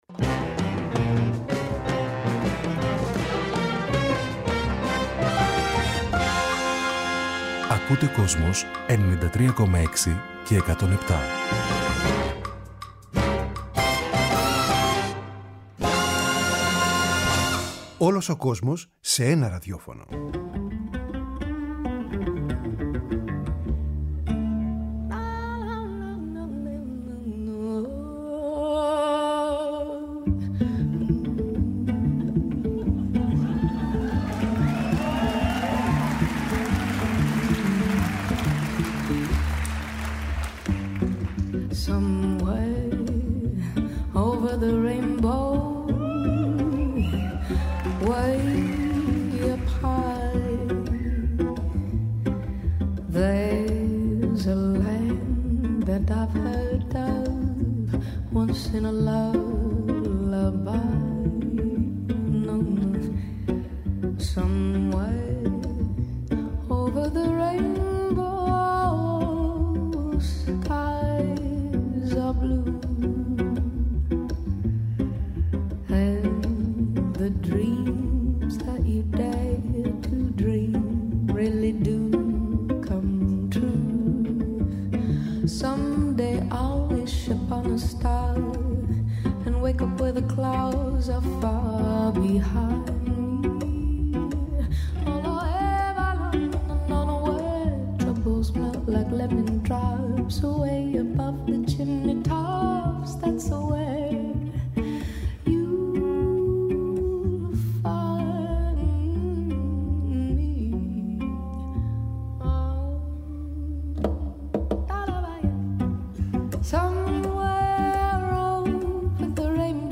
Το Kosmos υποδέχεται την ξεχωριστή μουσική περσόνα με την βελούδινη τζαζ φωνή, την μελωδική Μelody Gardot λίγες μέρες πριν την εμφάνιση της στο Ηρώδειο στις 12 Ιουλίου.
συνέντευξη